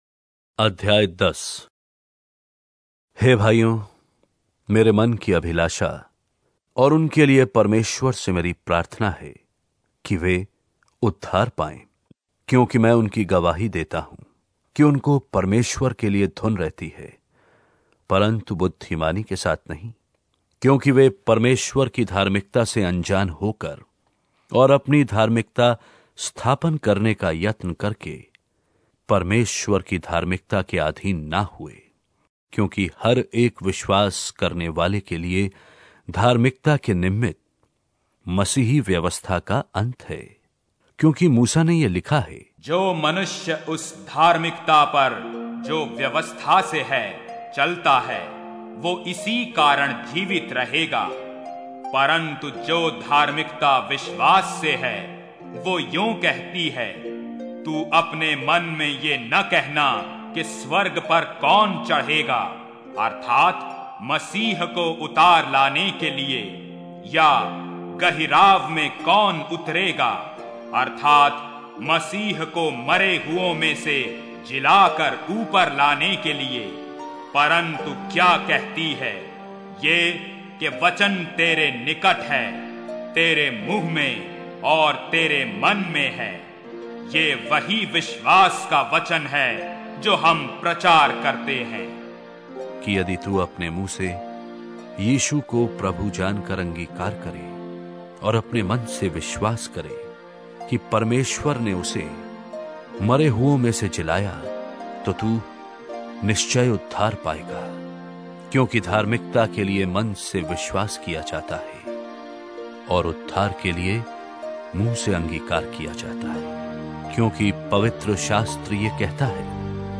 Hindi Drama Audio Bible New Testament